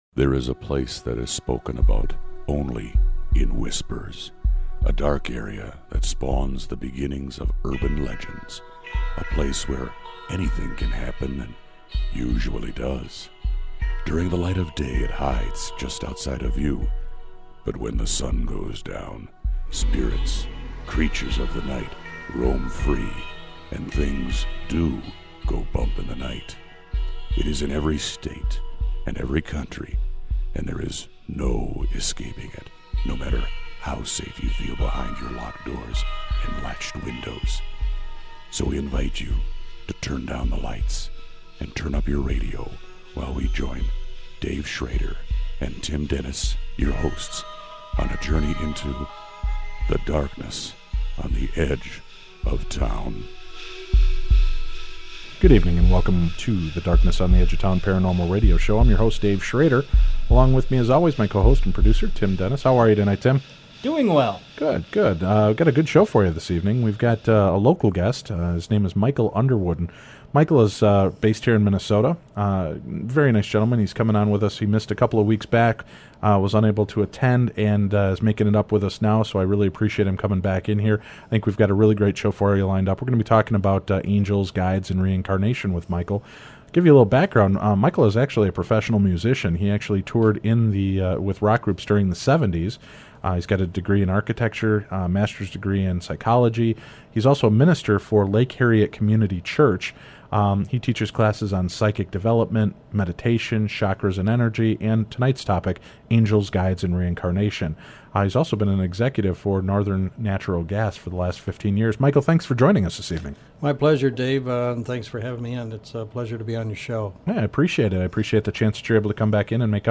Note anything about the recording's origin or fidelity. WARNING: The file cuts off halfway through!